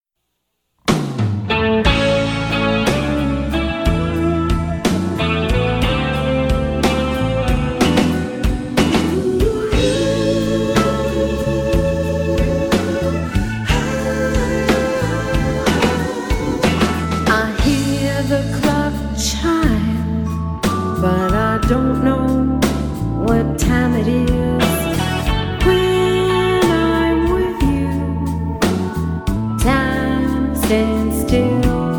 ballad
The musicianship is a powerhouse classic rock line up